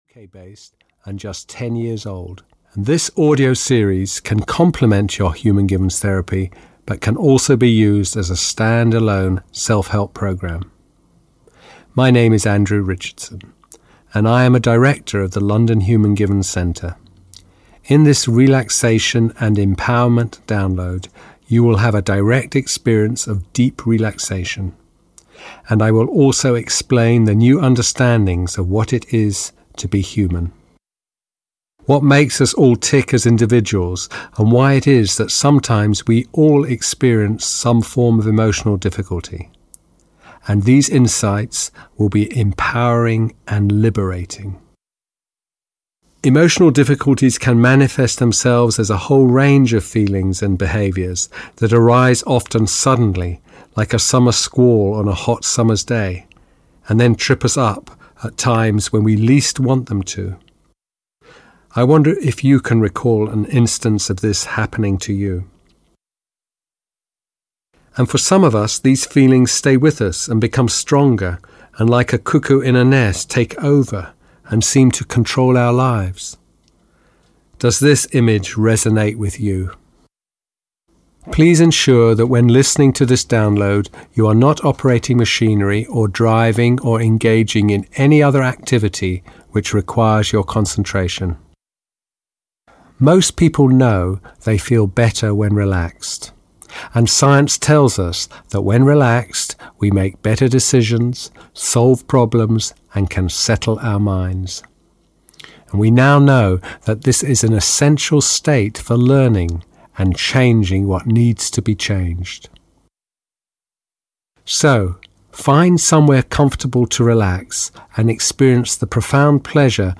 Audio knihaRelaxation and Empowerment (EN)
Ukázka z knihy